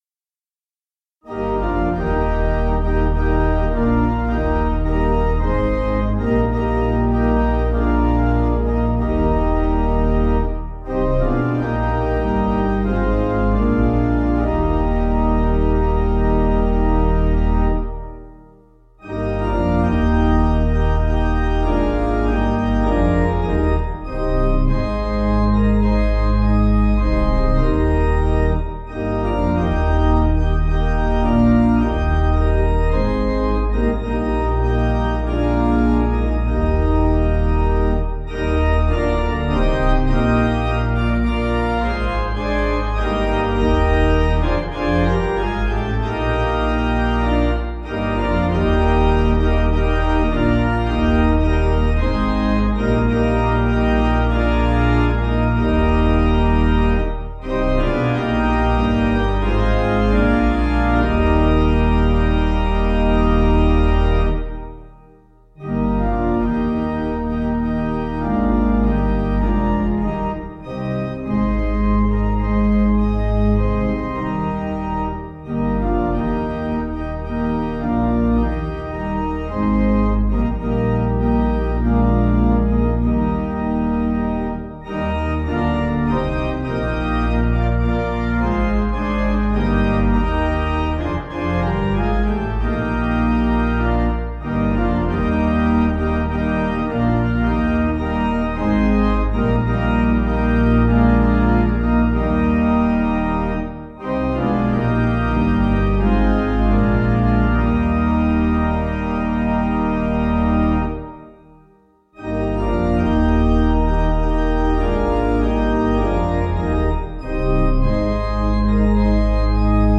Key: D Major Source: Scottish melody